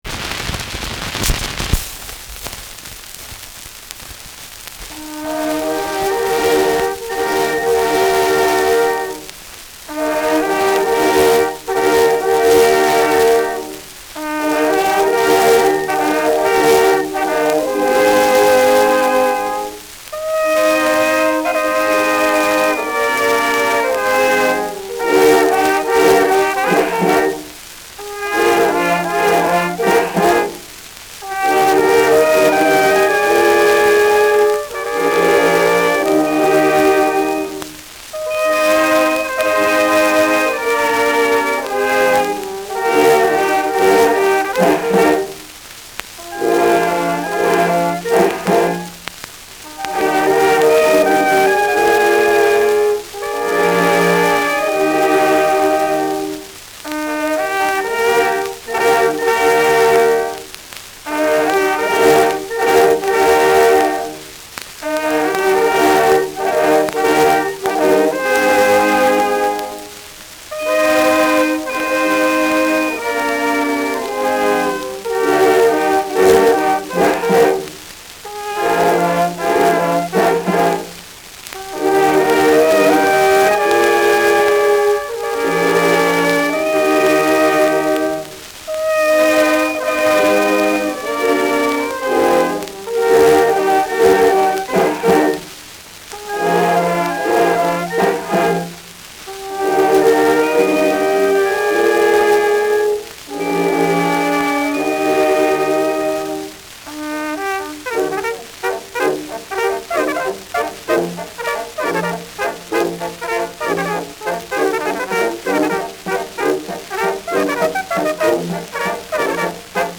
Schellackplatte
Abgespielt : Leiern : Teils leicht verzerrt